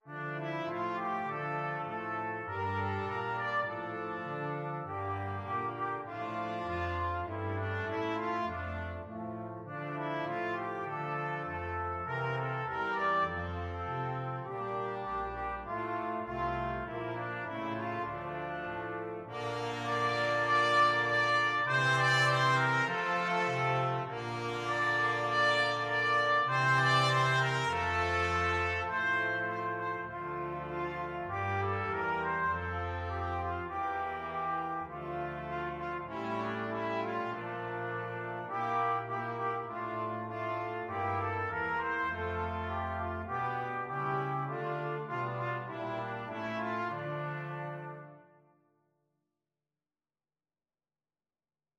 Trumpet 1Trumpet 2French HornTromboneTuba
Espressivo
4/4 (View more 4/4 Music)
Brass Quintet  (View more Easy Brass Quintet Music)
World (View more World Brass Quintet Music)